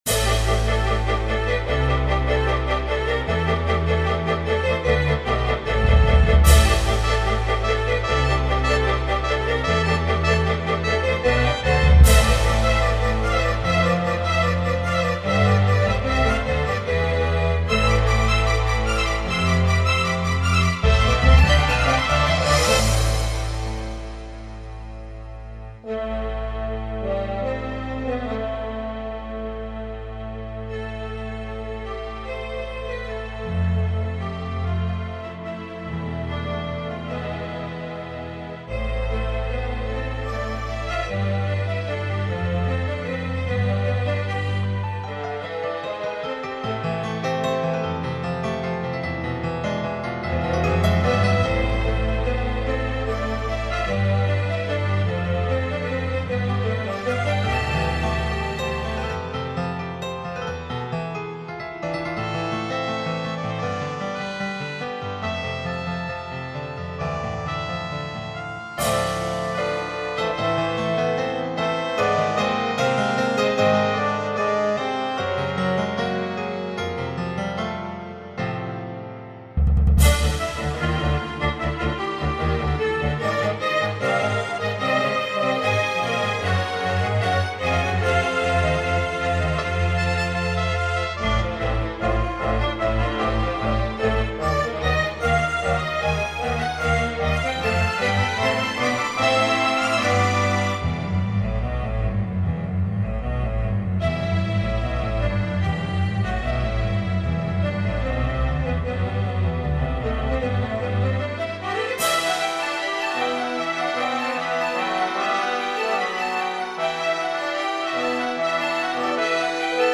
Celargirith (strings, brass, and percussion) – 2001